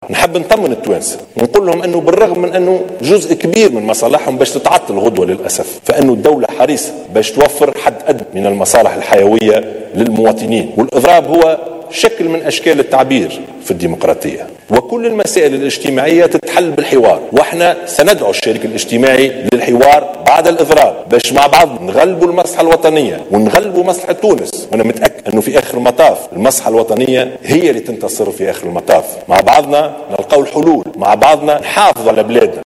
وقال الشاهد في كلمة خلال النشرة الرئيسية للأنباء على قناة الوطنية الأولى، إن الدولة حريصة على توفير الحد الأدنى من المصالح الحياتية للمواطنين غدا الخميس يوم إضراب أعوان الوظيفة العمومية والقطاع العام.